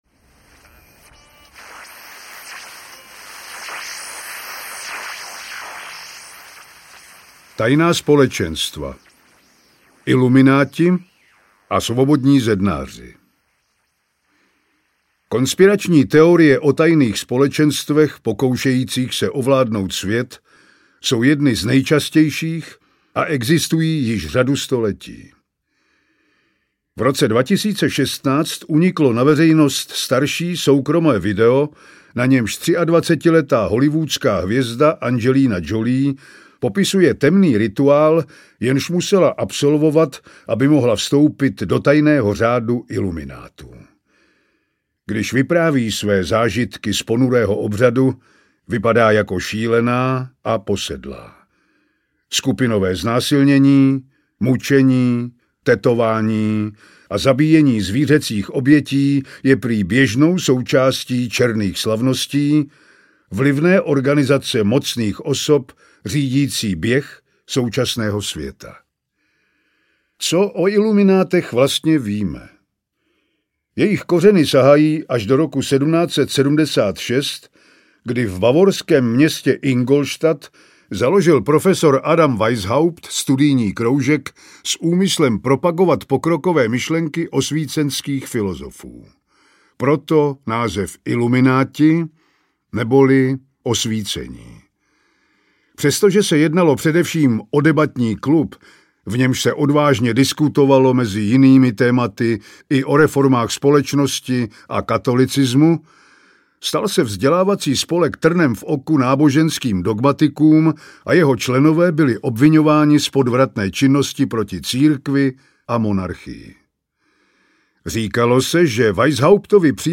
Nejznámější konspirační teorie audiokniha
Ukázka z knihy
• InterpretPavel Rímský